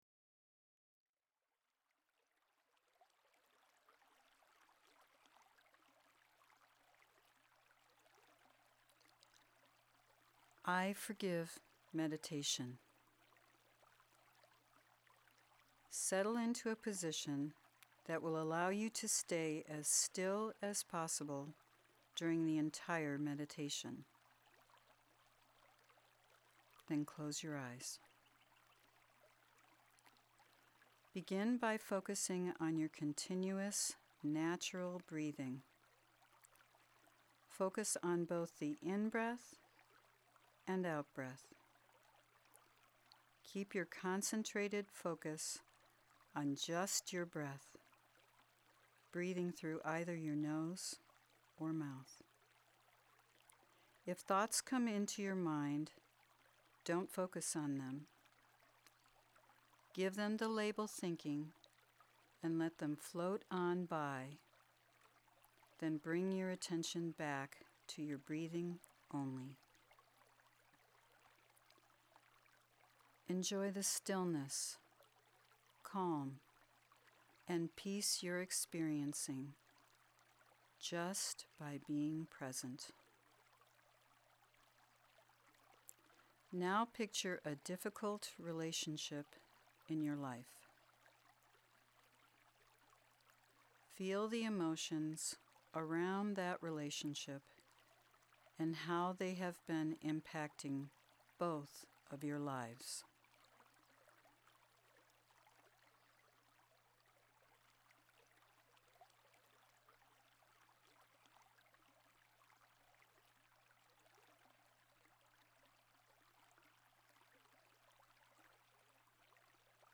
Download Meditation MP3